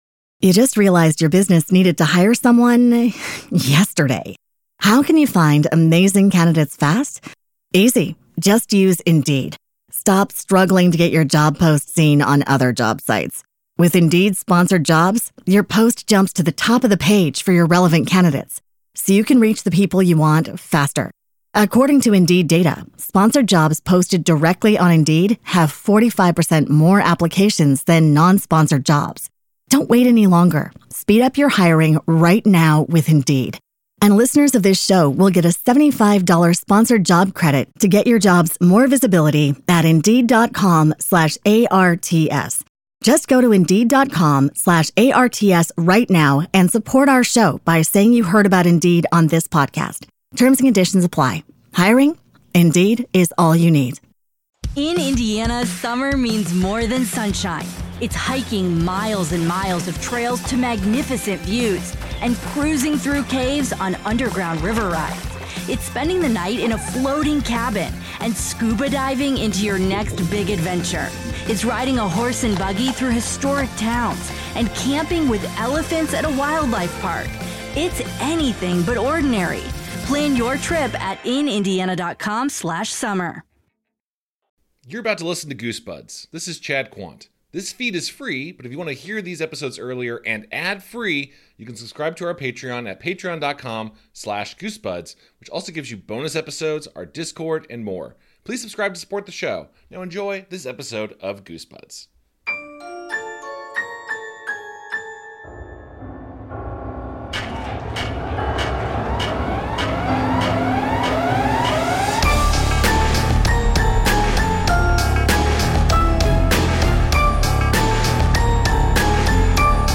Comedy Interviews